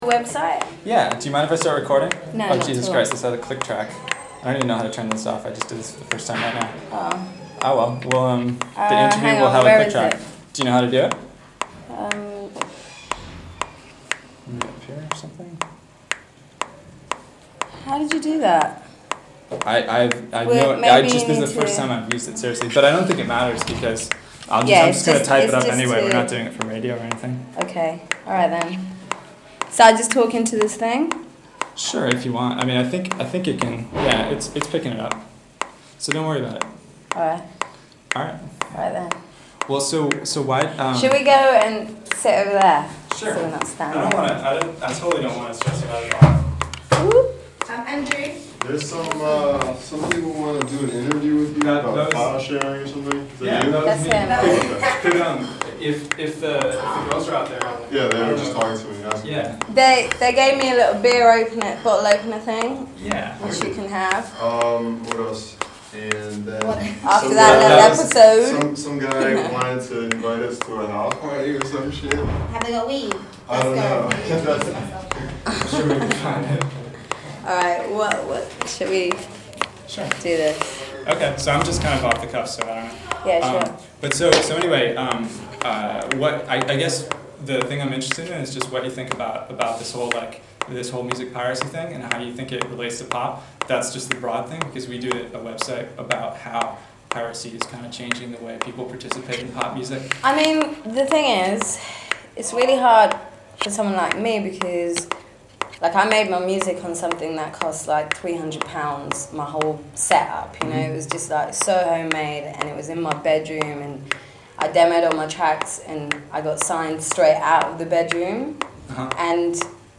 Enjoy the click track and the ambient intro.
M.I.A. interview with Downhill Battle.mp3